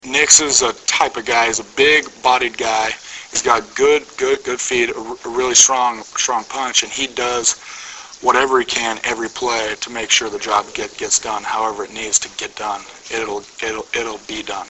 Preseason Press Conference